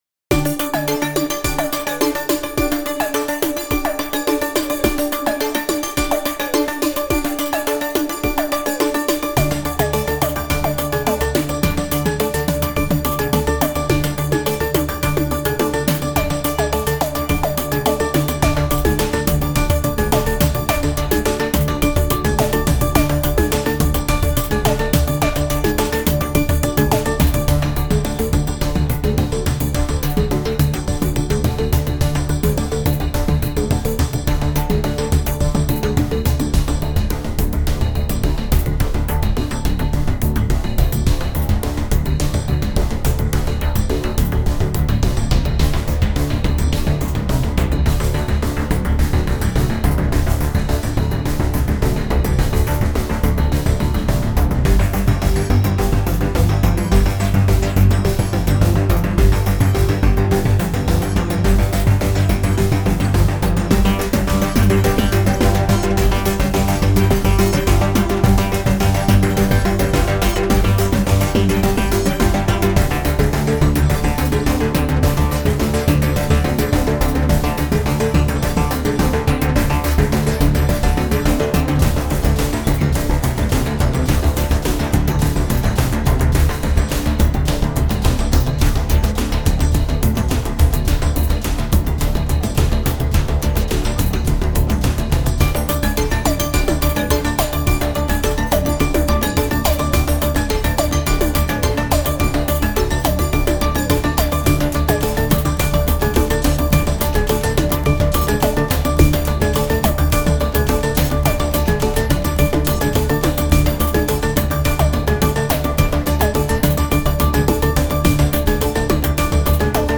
Today I composed a Powerful Synthesizer Soundtrack.